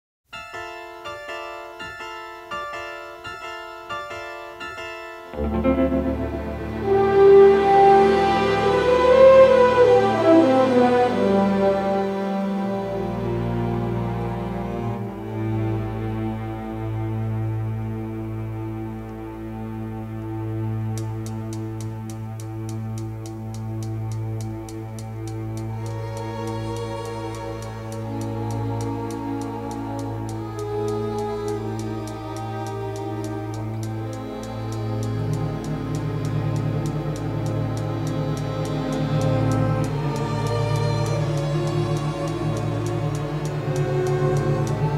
and a creepy score including harpsichord and solo soprano.